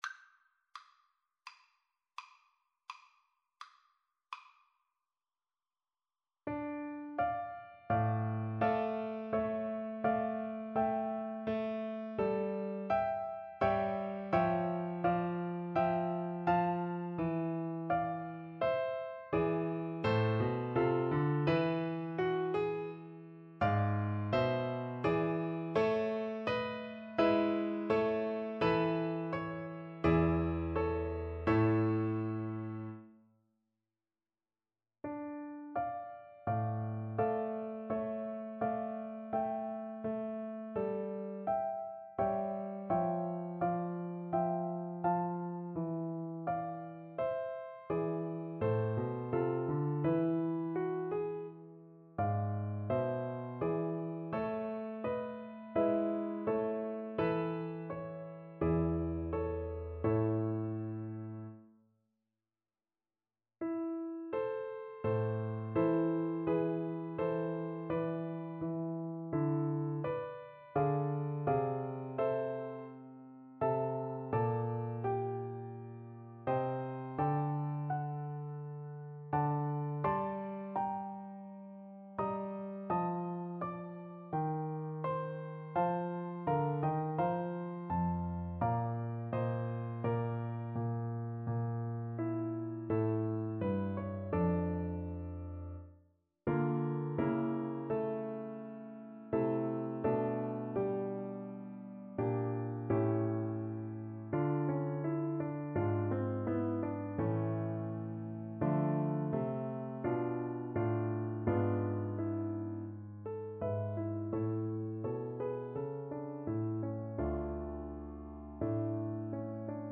Largo =42
Classical (View more Classical Recorder Music)